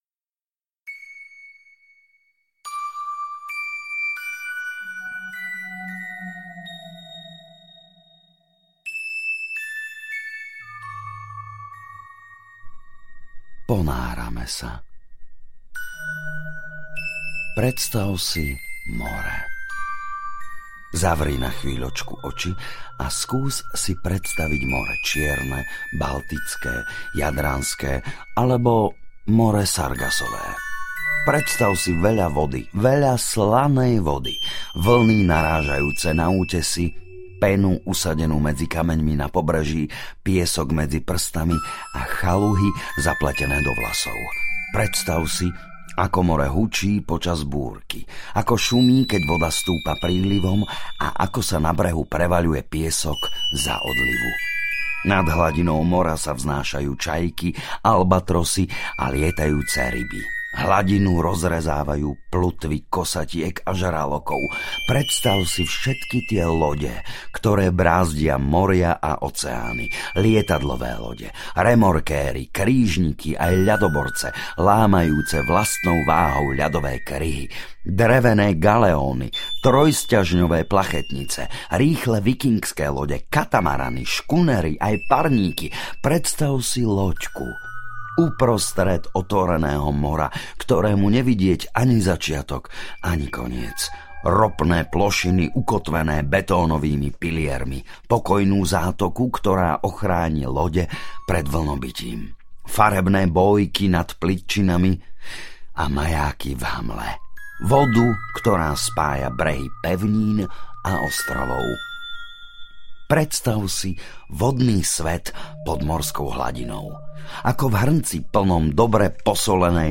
Hlbokomorské rozprávky audiokniha